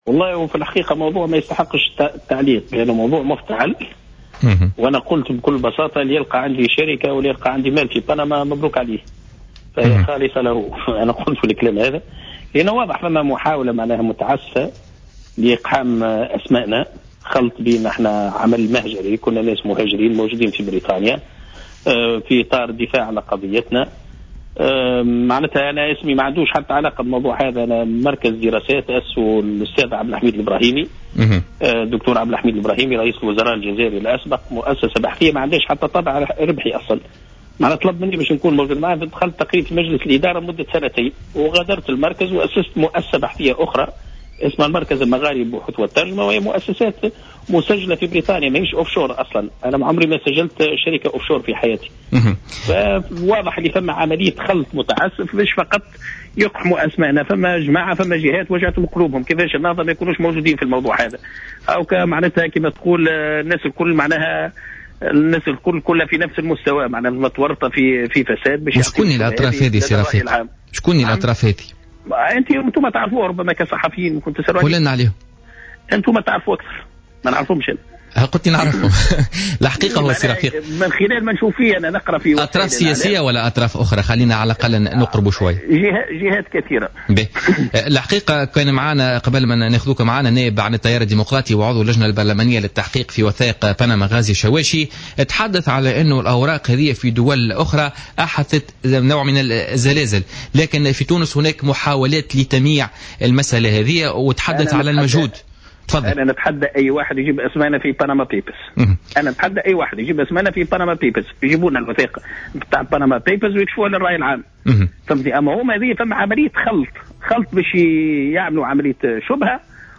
وأوضح عبد السلام في مداخلة له اليوم في برنامج "بوليتيكا" أن اسمه ورد بوصفه كان عضوا في مجلس إدارة المركز المغاربي للدراسات الإسلامية الذي يترأسه عبد الحميد الإبراهيمي، رئيس الوزراء الجزائري الأسبق، مؤكدا أن هذه المؤسسة ليس لها إي طابع ربحي وهي مسجلة في بريطانيا ولا علاقة لها بأوراق بنما.